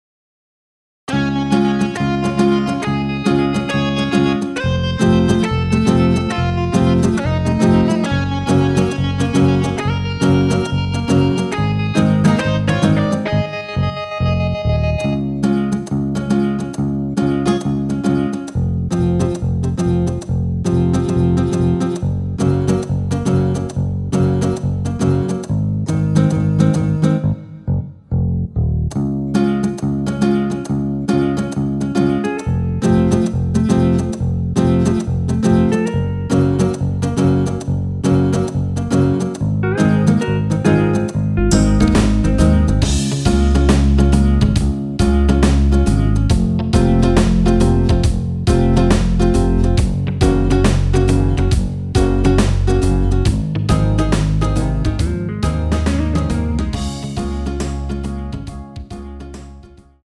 원키에서(-1)내린 MR입니다.
Db
앞부분30초, 뒷부분30초씩 편집해서 올려 드리고 있습니다.
중간에 음이 끈어지고 다시 나오는 이유는